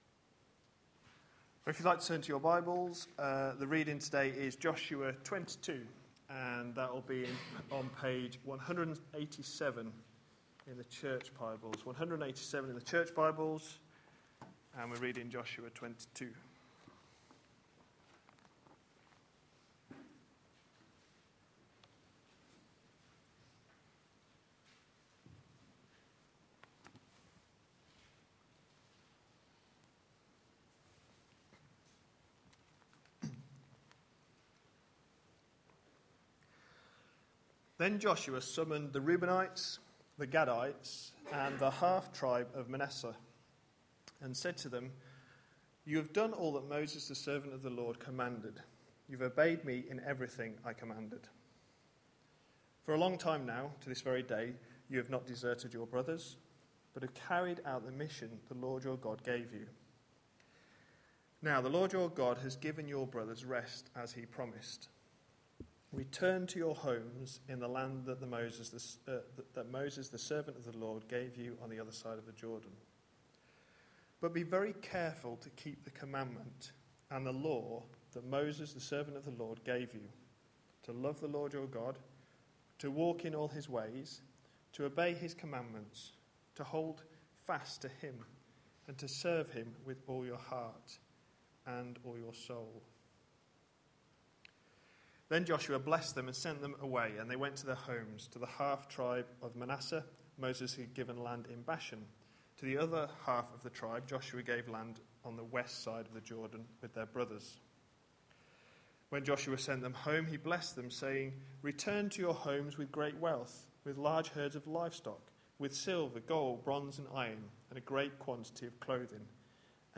A sermon preached on 8th July, 2012, as part of our Entering God's Rest series.